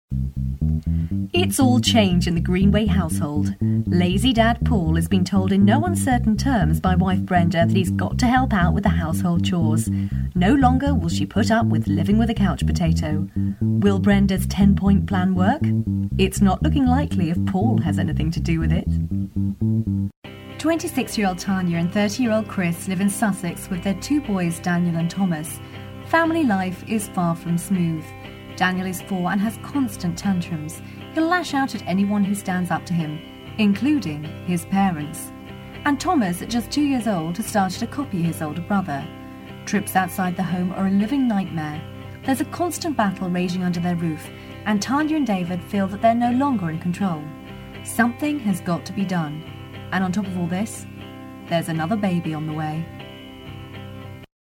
• Native Accent: London, RP
• Home Studio